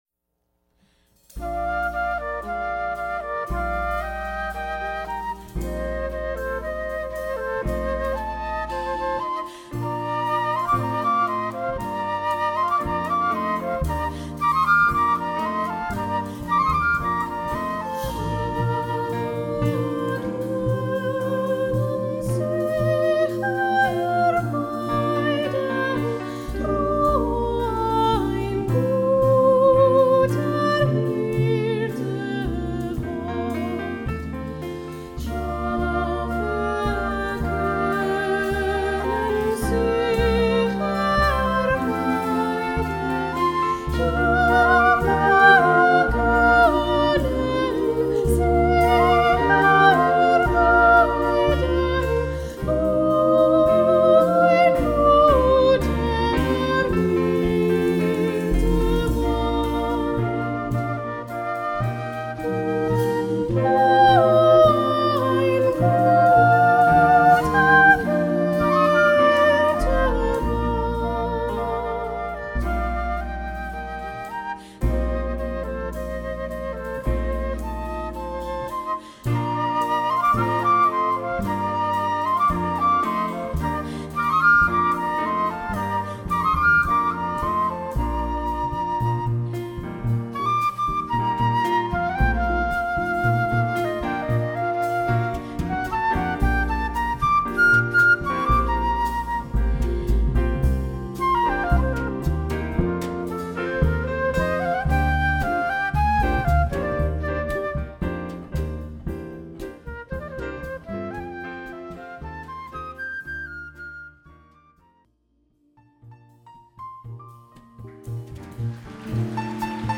Soprano solo, SATB, 2 flutes, rhythm
Baroque music at its best with a jazz flavor.